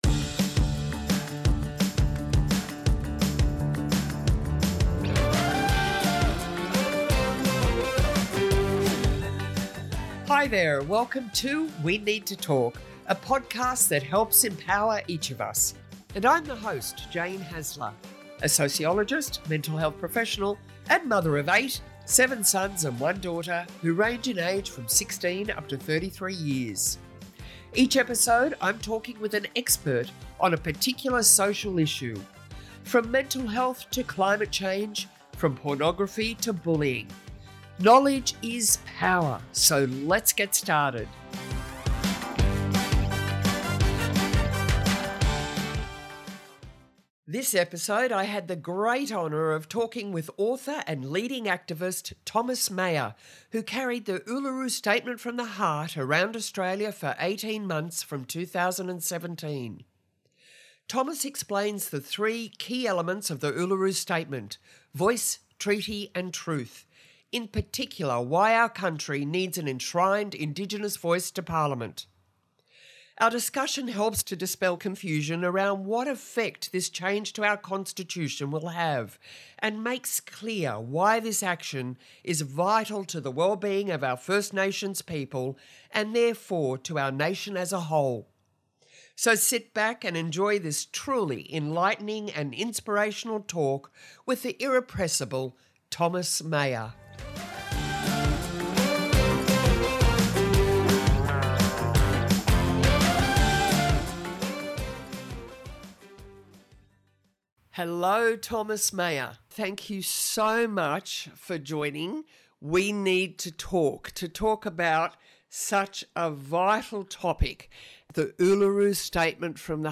This episode I had the great honour of talking with author and leading activist, Thomas Mayor, who carried the Uluru Statement from the Heart around Australia for 18 months from 2017. Thomas explains the three key elements of the Uluru Statement - Voice, Treaty and Truth, in particular, why our country needs an Enshrined Indigenous Voice to parliament. Our discussion helps to dispel confusion around what effect this change to our constitution will have and makes clear why this action is vital to the wellbeing of our First Nations people and therefore, to our nation as a whole.